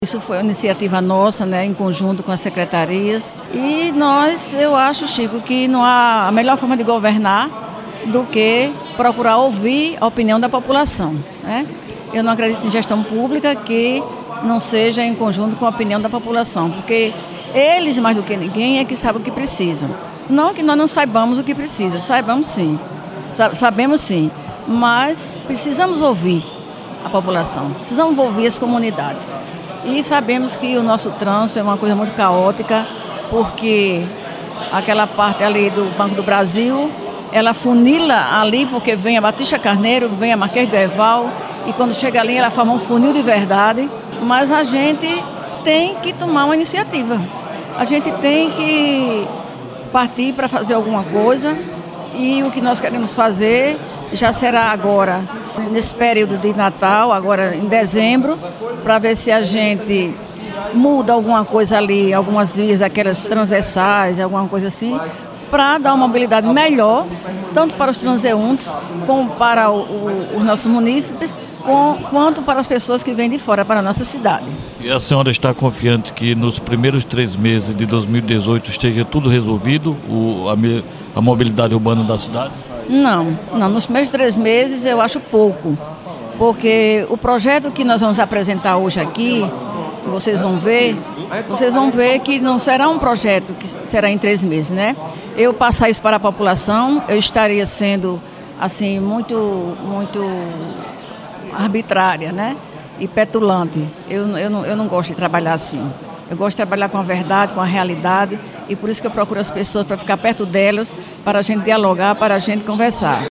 Ouça o áudio da prefeita, sobre o melhoramento da mobilidade urbana. http